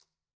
Conga-Tap1_v1_rr1_Sum.wav